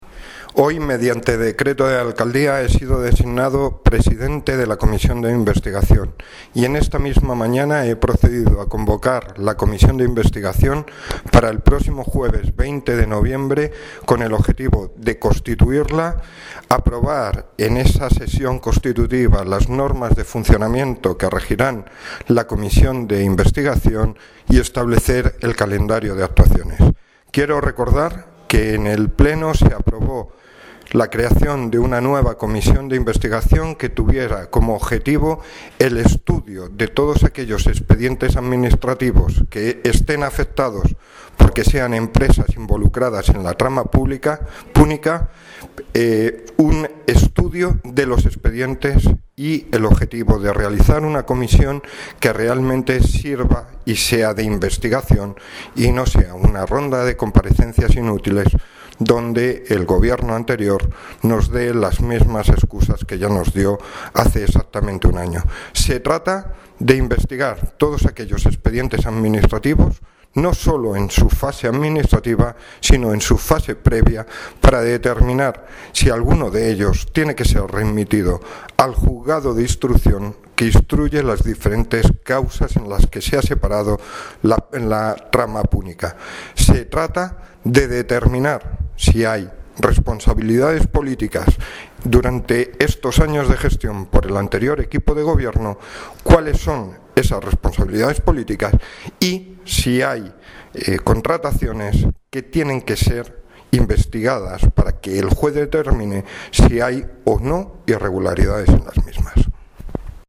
Audio - Javier Gómez (Concejal de Hacienda, Patrimonio) sobre Comision Sonido 1